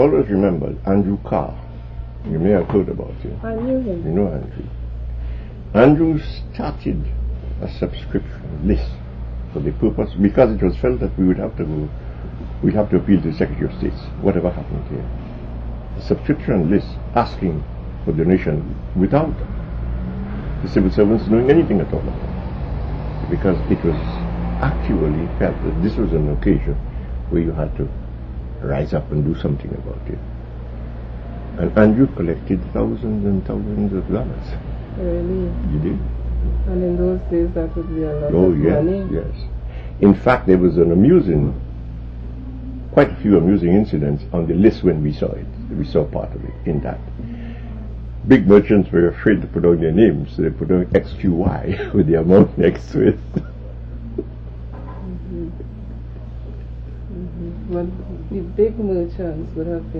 2 audio cassettes